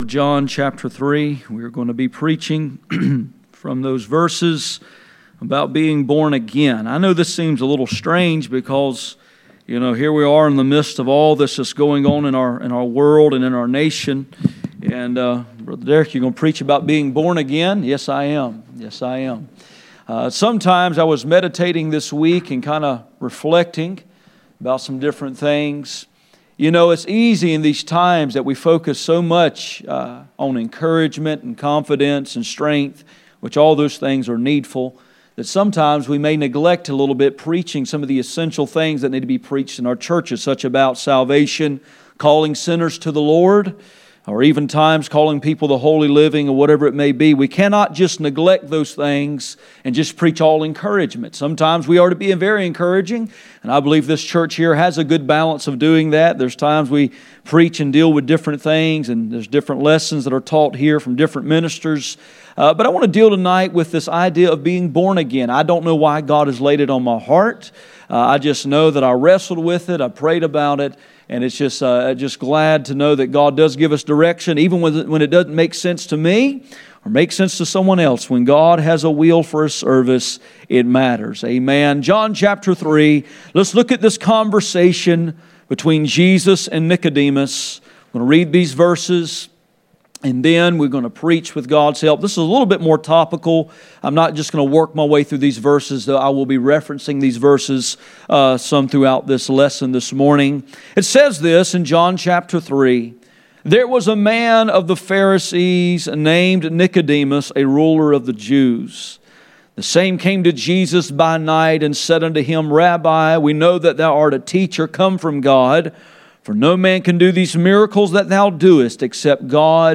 John 3:1-9 Service Type: Sunday Morning %todo_render% « Problems stated